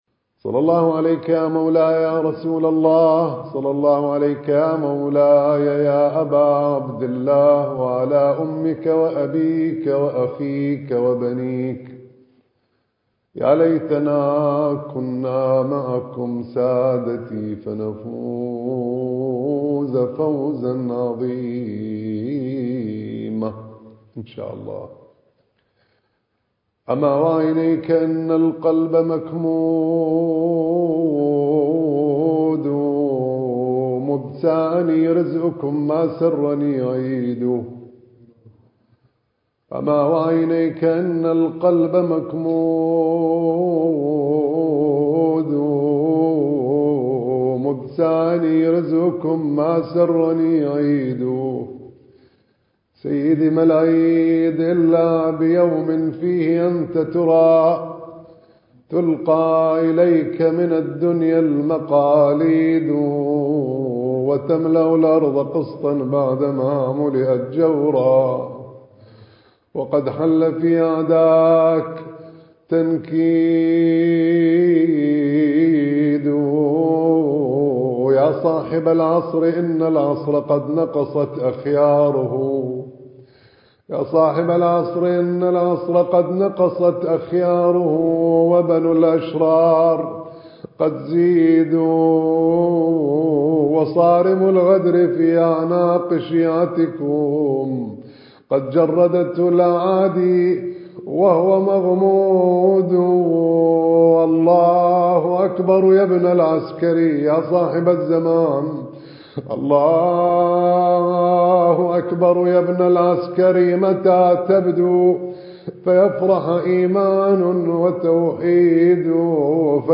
المكان: الحسينية المهدية - مركز الدراسات التخصصية في الإمام المهدي (عجّل الله فرجه) - النجف الأشرف